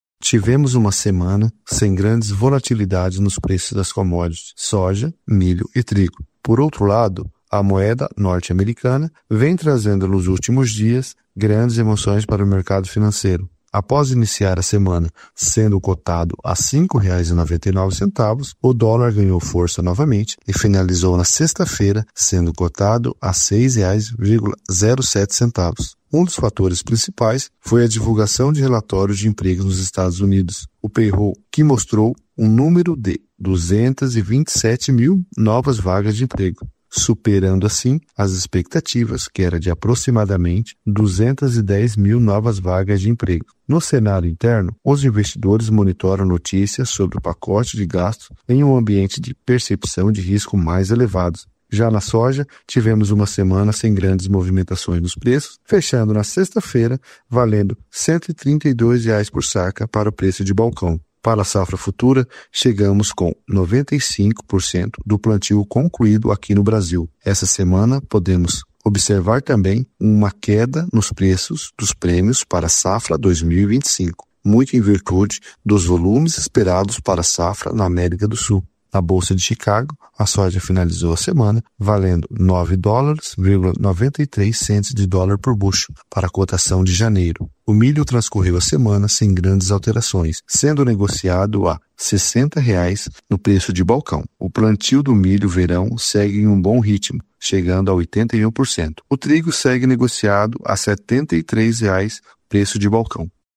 ÁUDIO – Comentário Mercado Agropecuário 09.12.2024